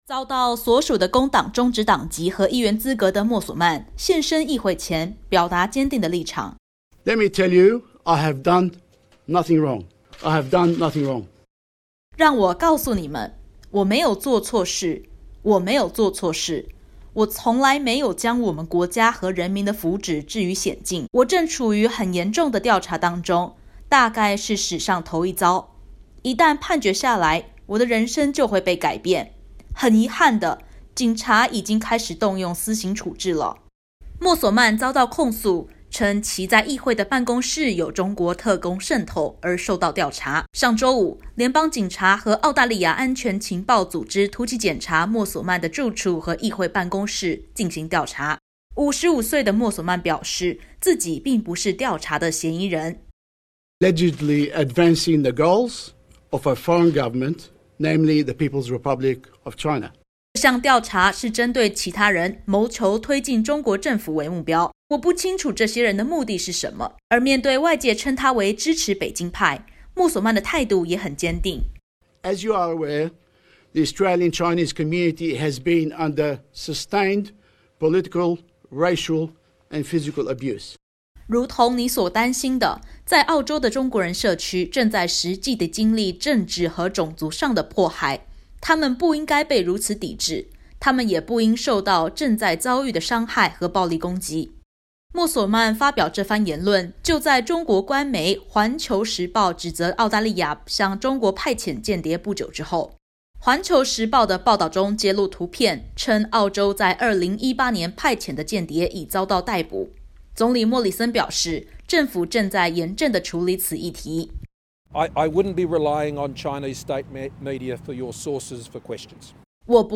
新州工党上议院议员肖凯·莫索曼（Shaoquett Moselmane）被控他的办公室有中国政府特工渗透，本案仍在调查当中， 不过，莫索曼全盘否认。点击图片收听完整报导。